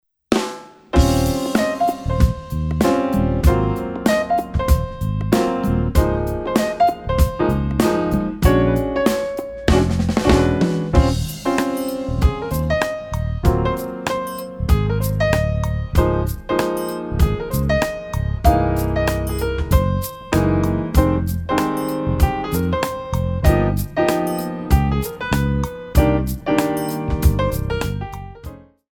4 bar intro
up-tempo
Jazz / Tap